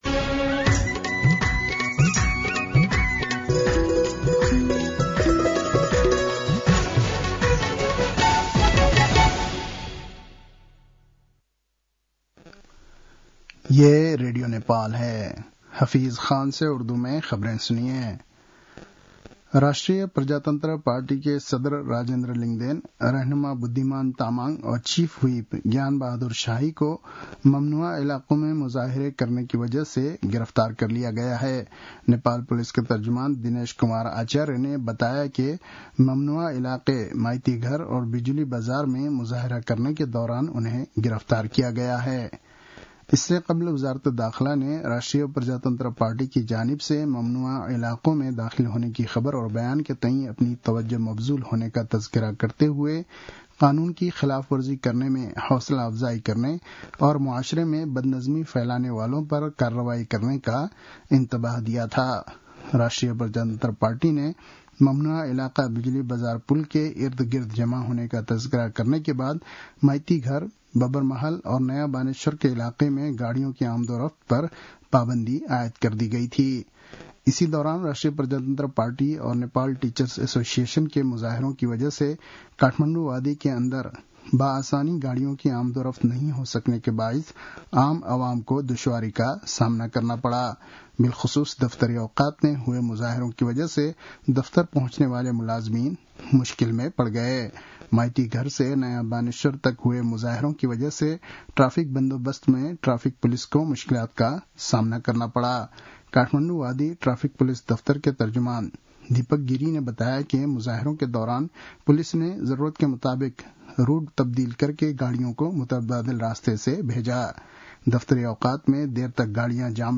An online outlet of Nepal's national radio broadcaster
उर्दु भाषामा समाचार : ७ वैशाख , २०८२